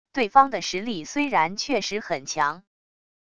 对方的实力虽然确实很强wav音频生成系统WAV Audio Player